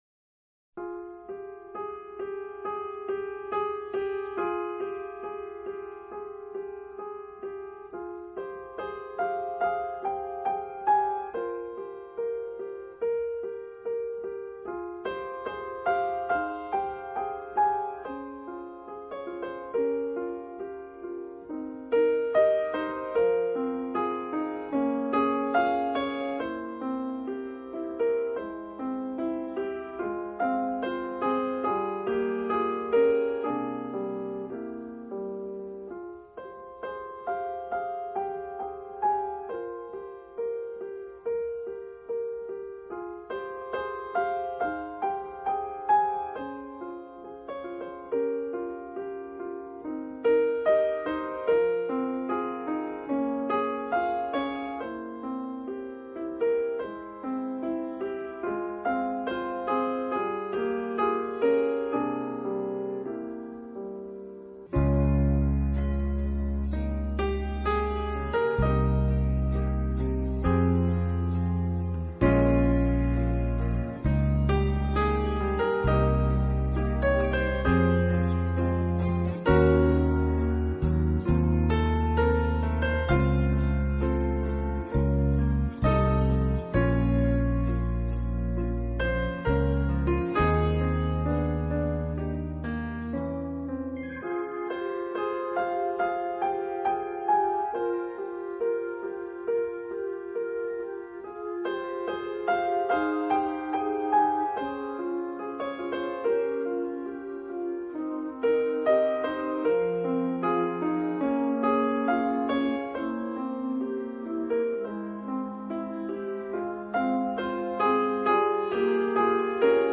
【钢琴专辑】